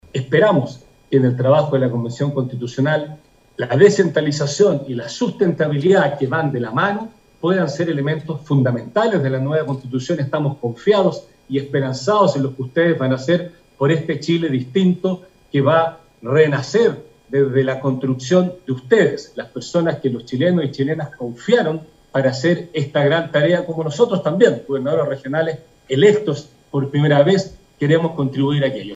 Fue el Gobernador Regional, Patricio Vallespín, quien vía telemática entregó un saludo protocolar de bienvenida a los constituyentes dejando de manifiesto la importancia de descentralizar el país.